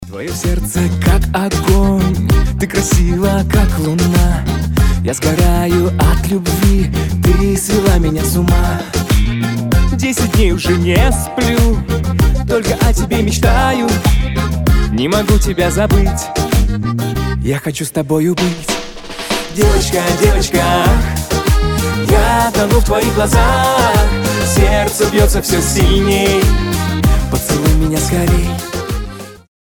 • Качество: 320, Stereo
поп
мужской вокал
русский шансон